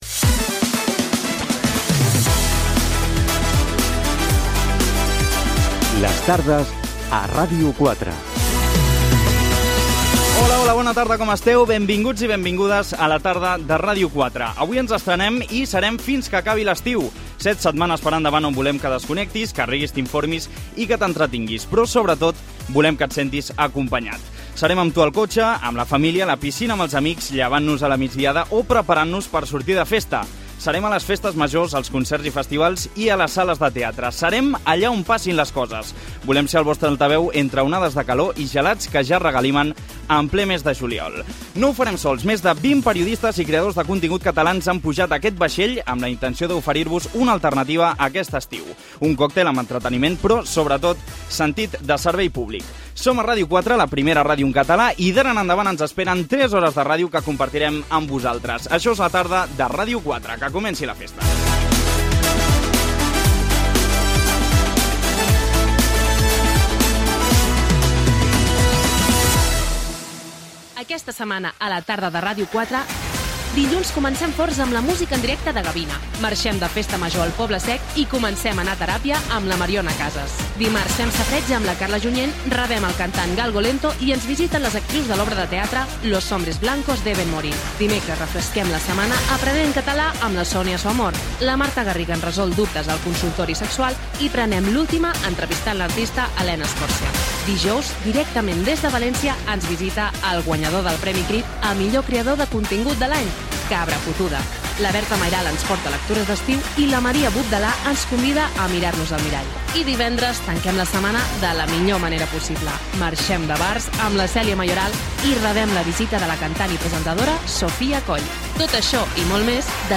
Careta del programa
diàleg dels presentadors